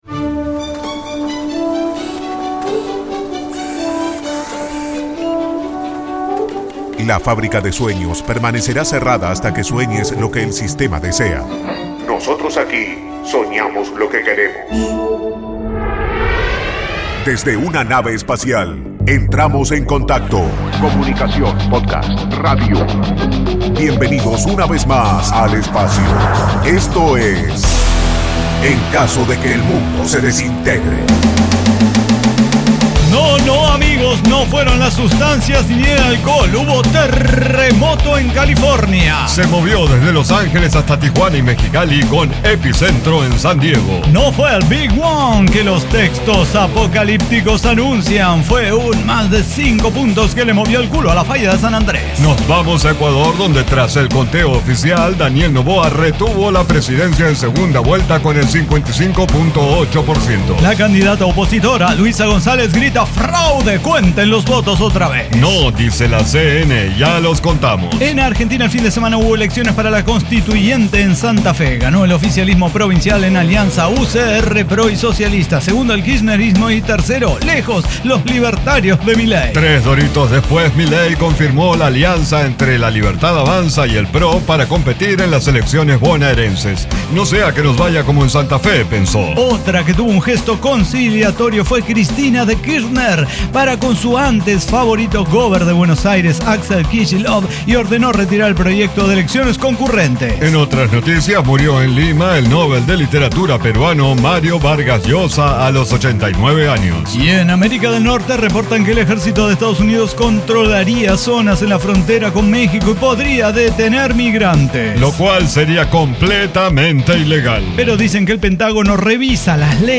Diseño, guionado, música, edición y voces son de nuestra completa intervención humana.